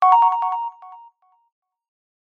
alarm-sound-3.mp3